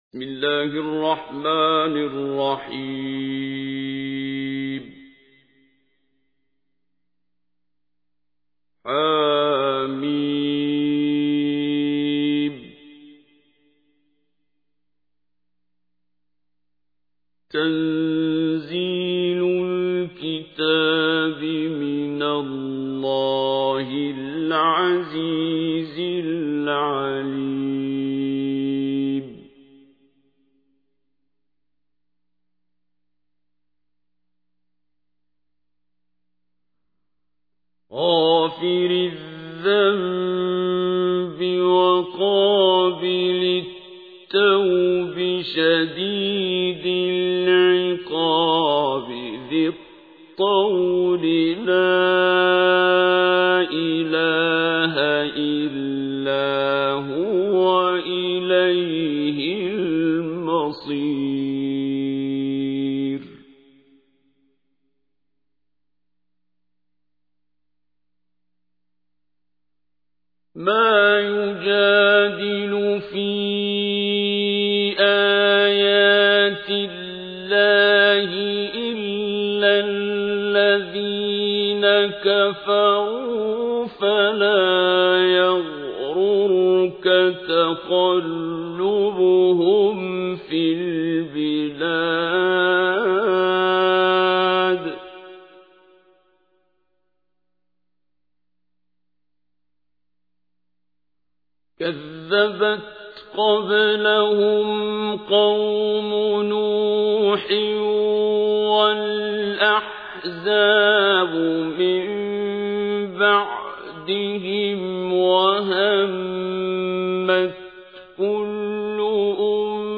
تحميل : 40. سورة غافر / القارئ عبد الباسط عبد الصمد / القرآن الكريم / موقع يا حسين